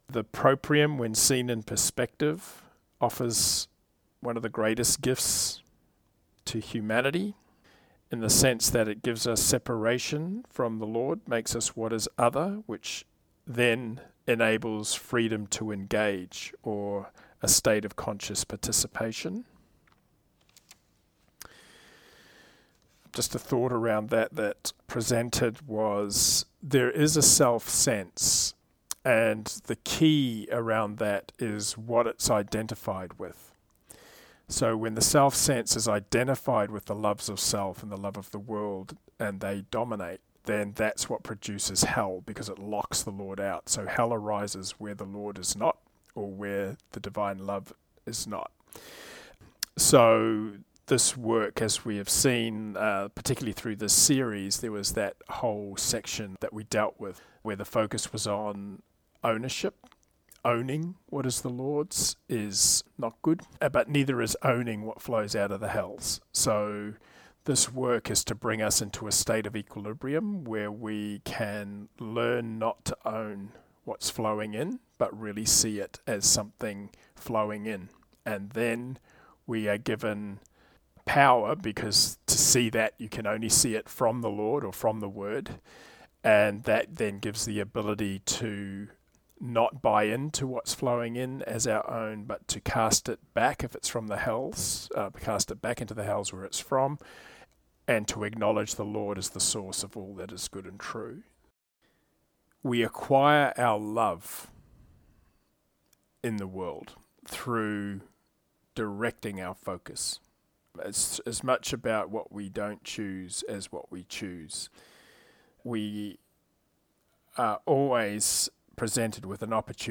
Third Round posts are short audio clips taken from Round 3 comments offered in the online Logopraxis Life Group meetings. The aim is to keep the focus on understanding the Text in terms of its application to the inner life along with reinforcing any key LP principles that have been highlighted in the exchanges.